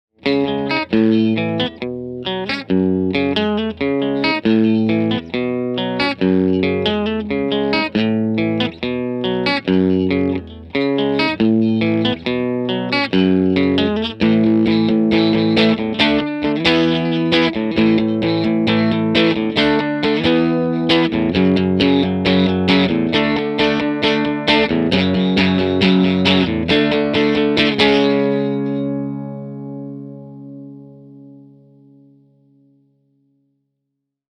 Actually, listening to these clips is a pretty good indication of the difference between the two - the Tele's a bit warmer and more refined, the Dano's got a bit more 'tude! 8) :D
Heh, those are more like what I call clean!
TC15_Ch2_Dirty_Crunch_Hi_hiINPUT_Tele.mp3